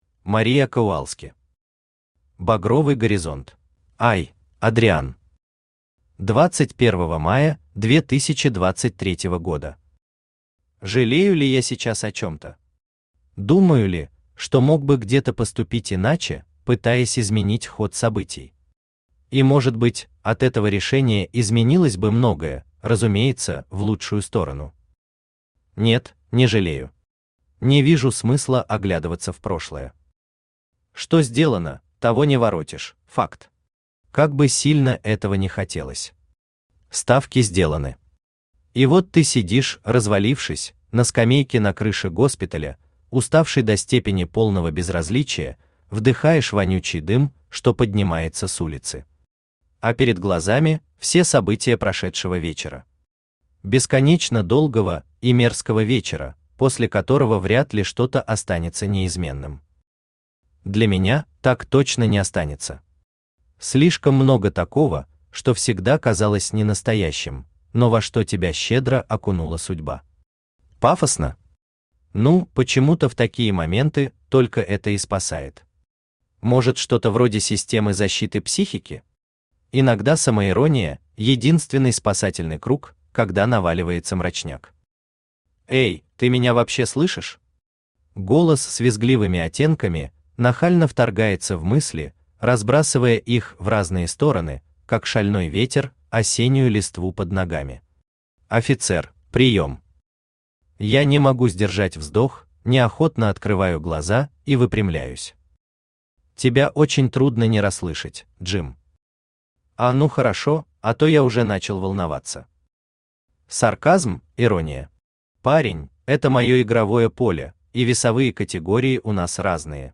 Аудиокнига Багровый горизонт | Библиотека аудиокниг
Aудиокнига Багровый горизонт Автор Maria Kowalsky Читает аудиокнигу Авточтец ЛитРес.